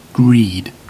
Ääntäminen
IPA : /griːd/